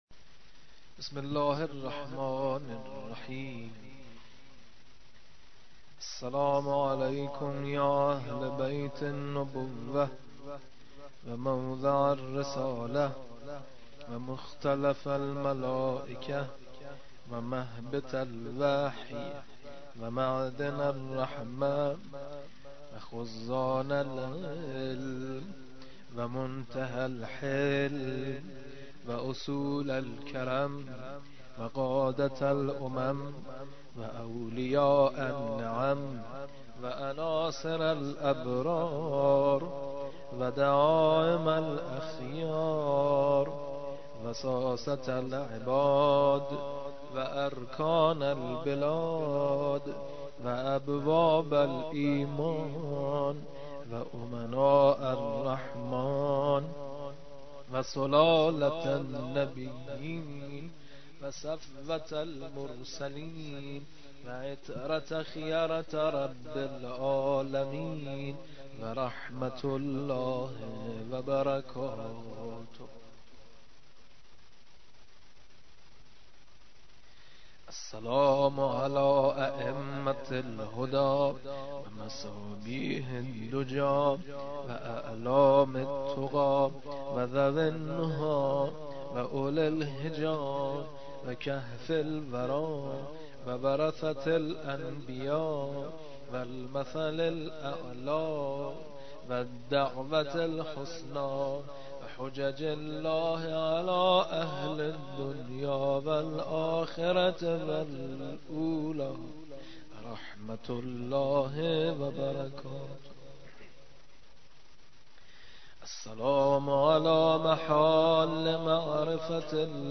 جلسه هفتگی؛ دوازدهم صفر 1434؛ روضه حضرت علی اصغر علیه السلام ؛ قسمت اول